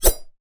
Звуки меча
Меч пролетел в сантиметре от горла